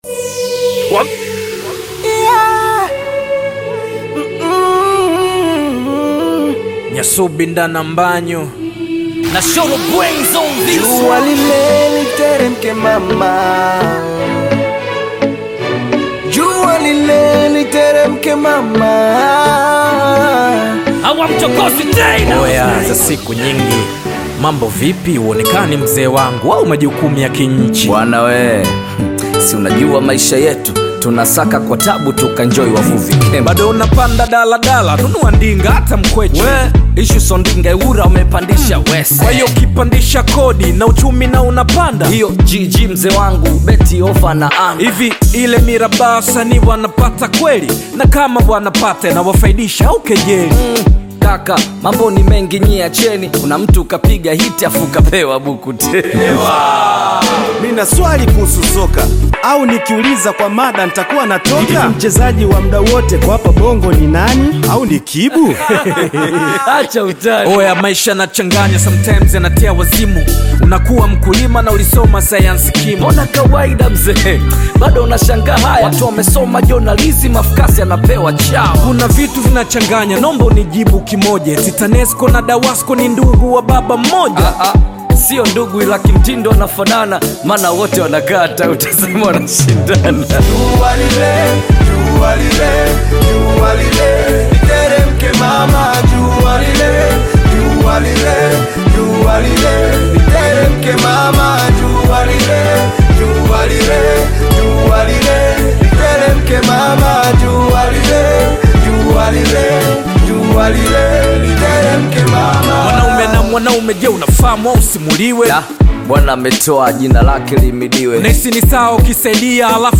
Tanzanian bongo flava artist, rapper, singer, and songwriter
hip-hop song